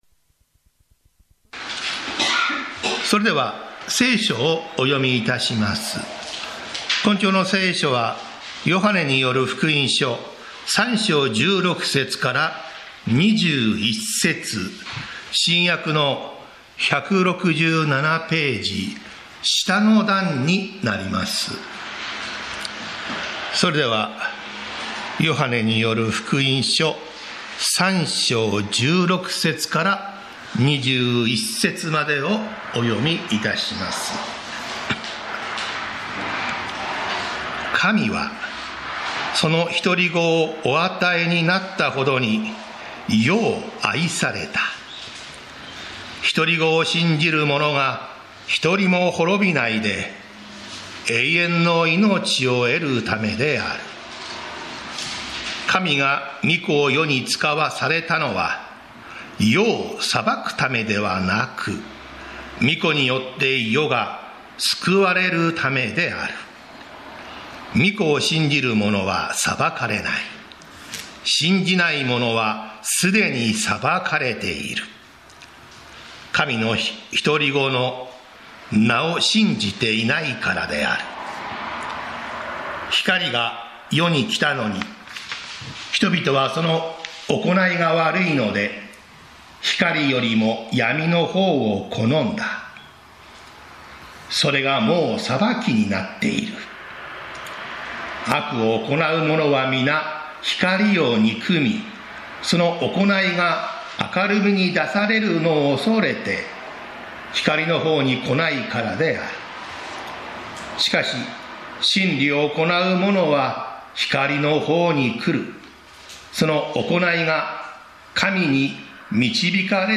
日曜 朝の礼拝